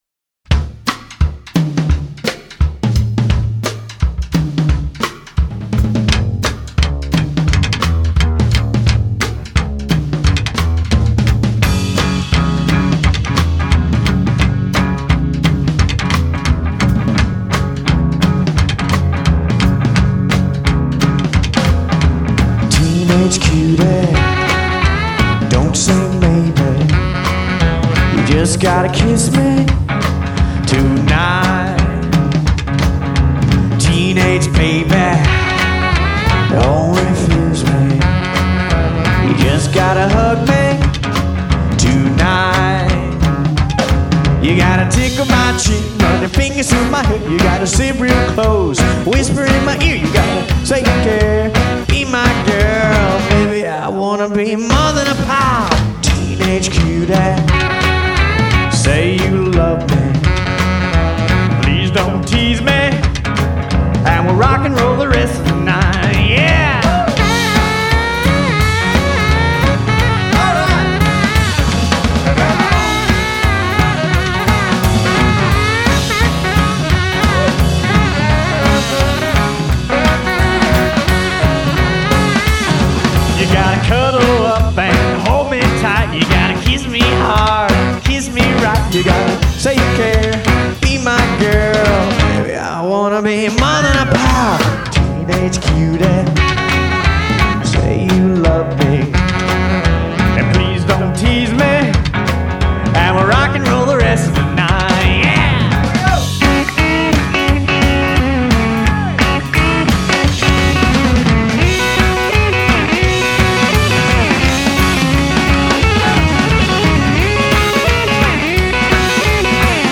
Vocals & Guitar
Bass & Vocals
Lead Guitar
Drums
Sax